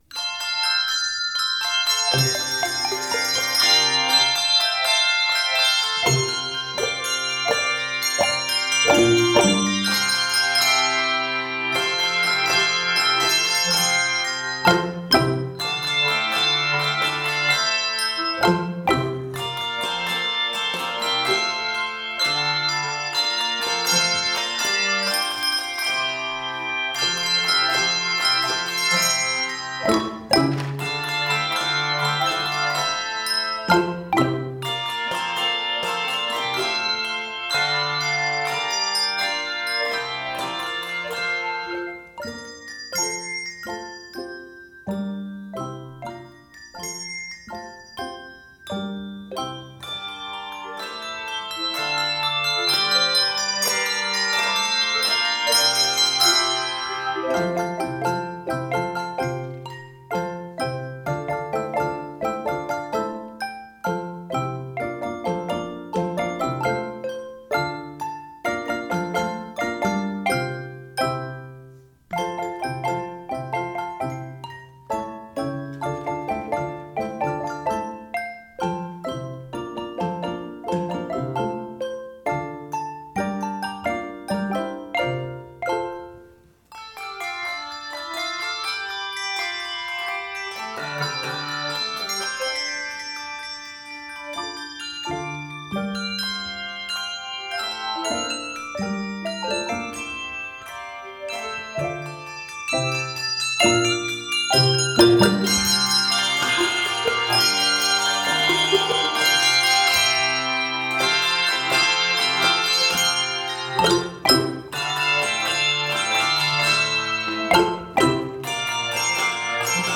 This fast-paced, exciting original composition